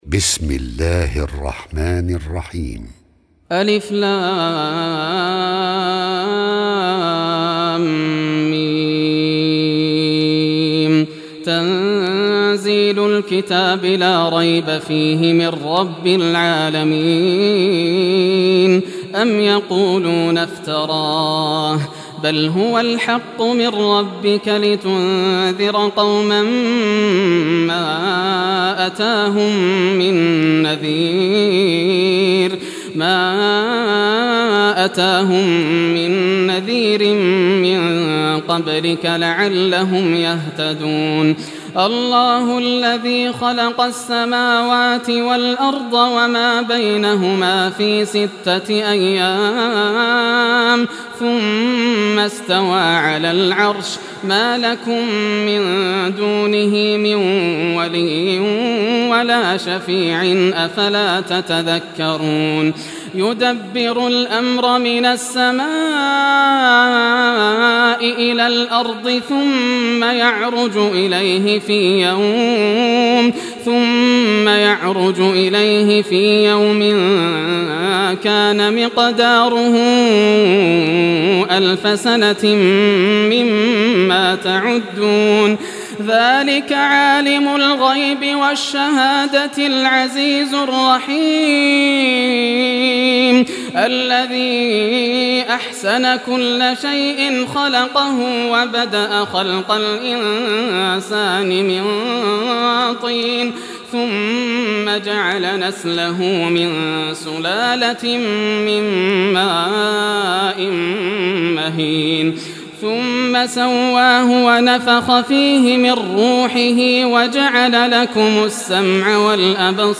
Surah As-Sajdah Recitation by Yasser al Dosari
Surah As-Sajdah, listen or play online mp3 tilawat / recitation in Arabic in the beautiful voice of Sheikh Yasser al Dosari.